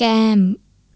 kAAm (sounds like 'cam') falling tone.